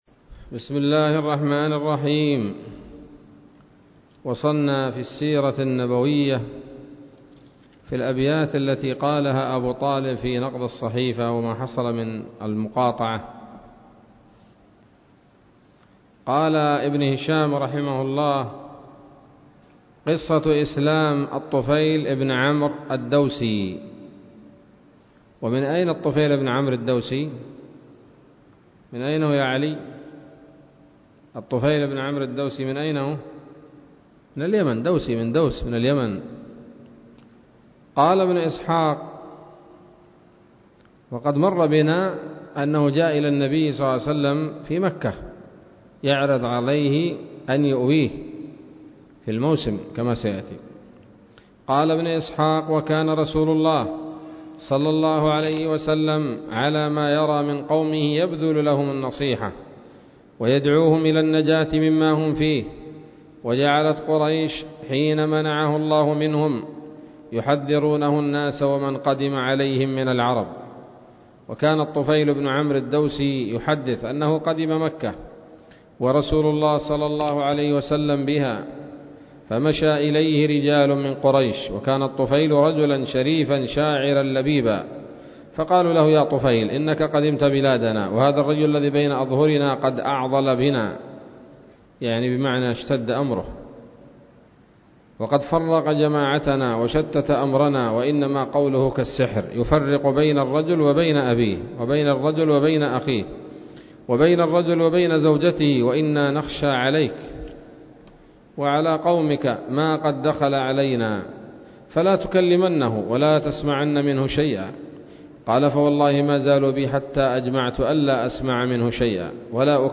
الدرس الرابع والأربعون من التعليق على كتاب السيرة النبوية لابن هشام